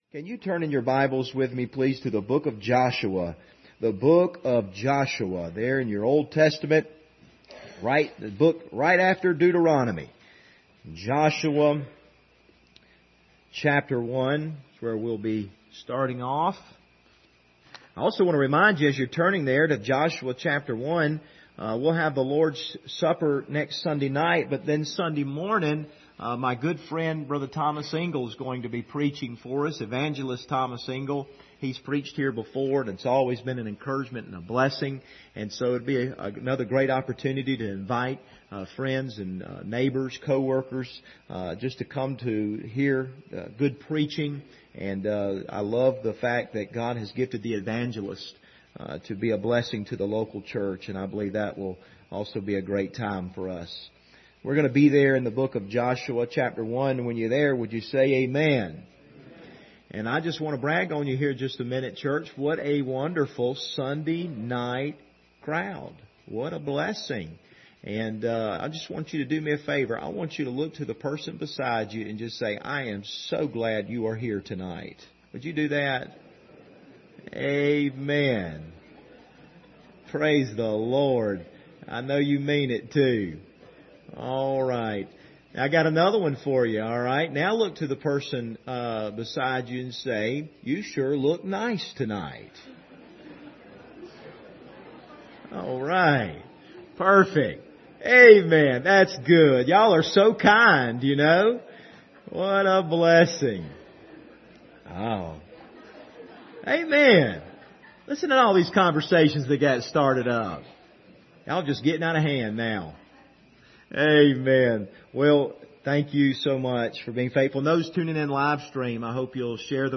Passage: Joshua 1 Service Type: Sunday Evening